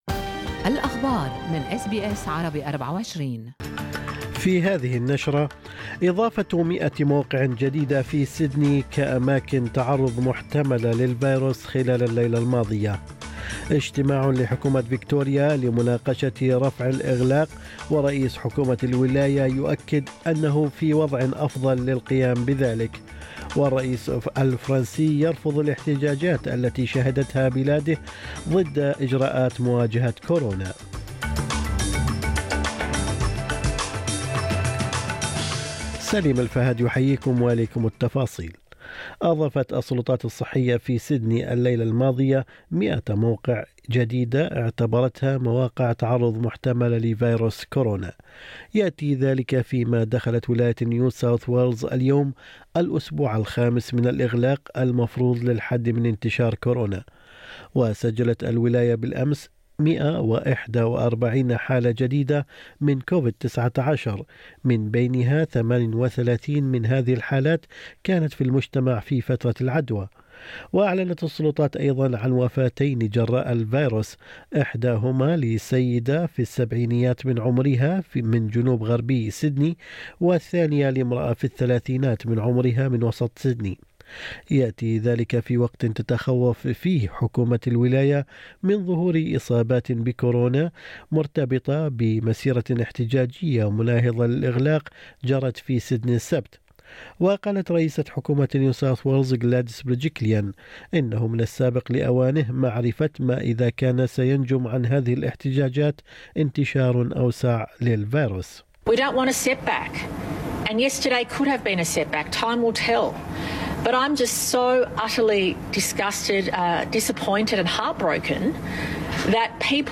نشرة أخبار الصباح 26/7/2021